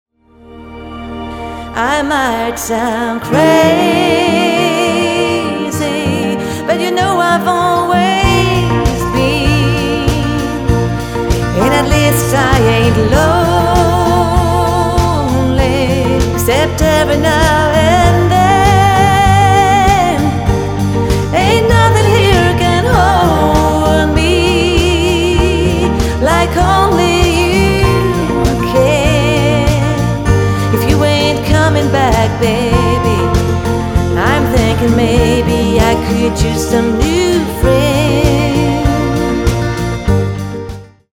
Tour de chant 100% country.